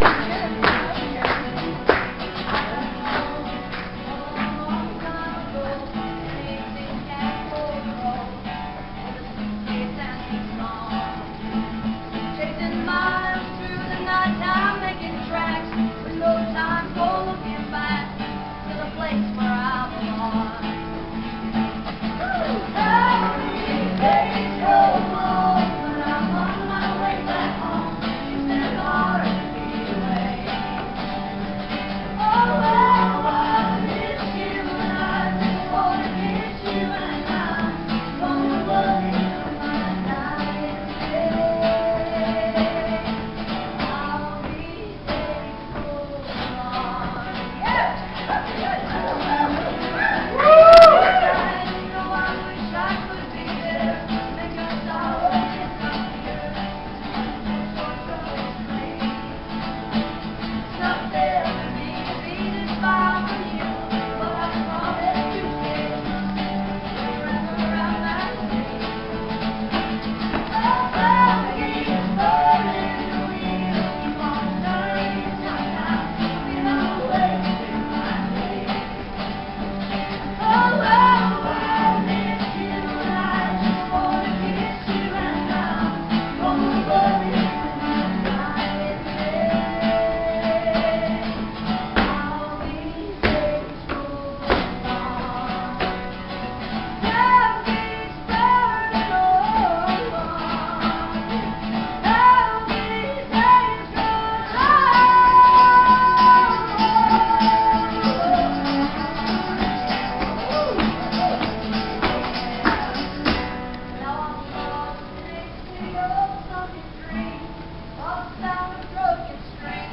(captured from youtube videos)
(completely unplugged)